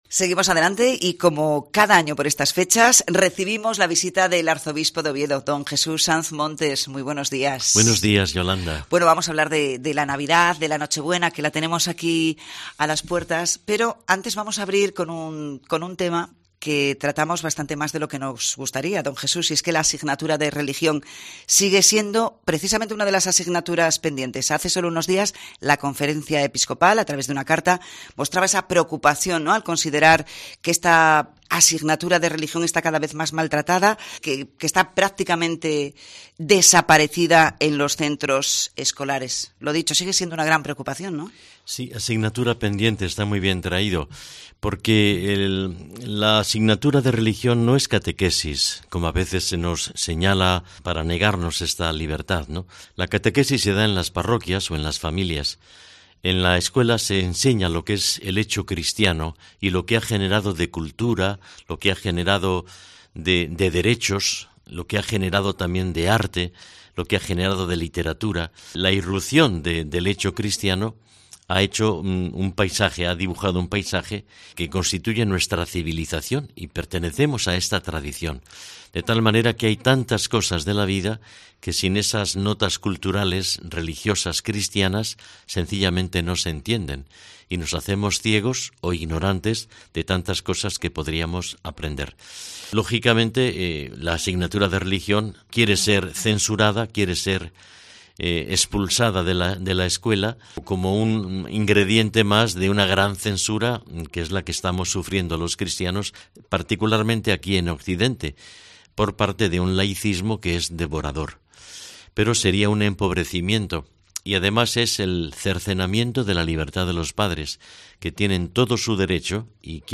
Entrevista al Arzobispo de Oviedo, don Jesús Sanz Montes